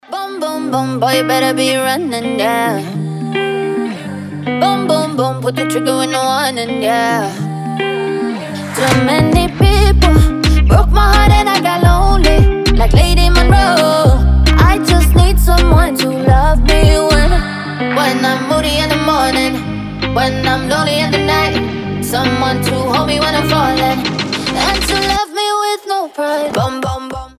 • Качество: 320, Stereo
заводные
женский голос
Dance Pop